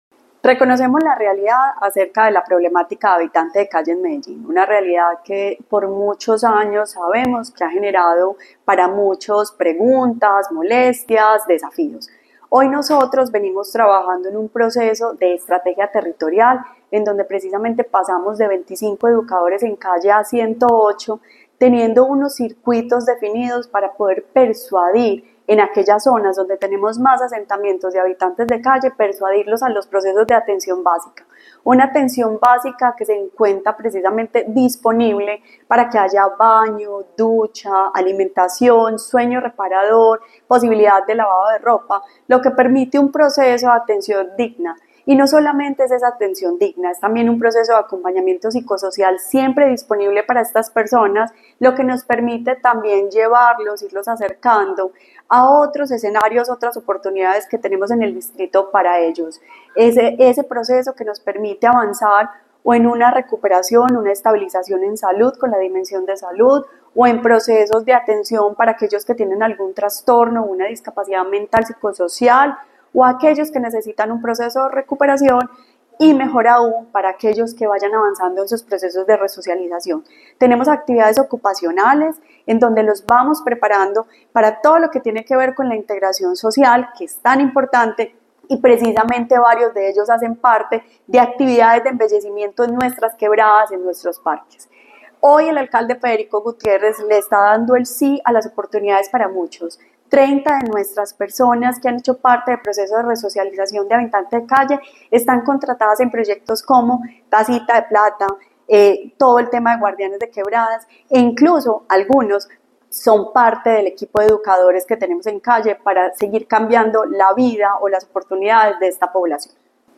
Palabras de Sandra Sánchez, secretaria de Inclusión Social y Familia Palabras de David Ramírez, subsecretario de Espacio Público